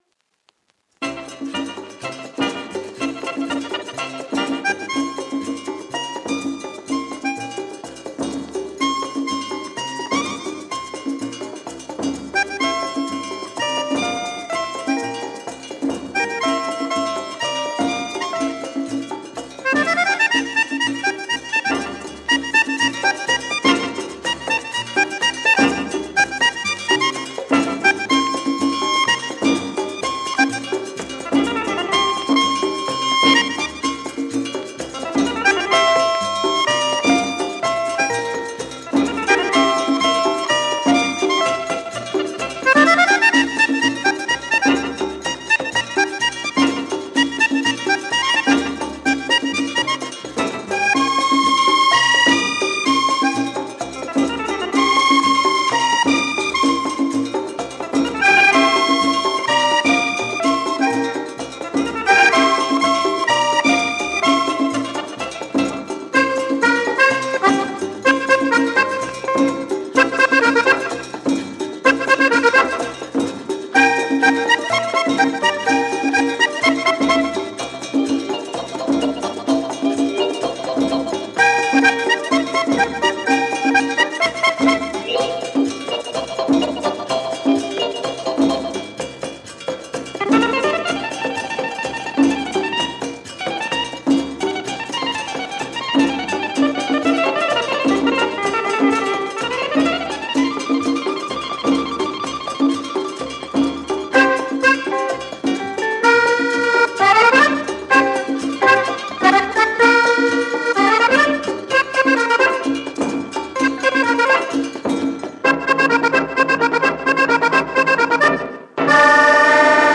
Rare Exotica Tityshaker Latin French EP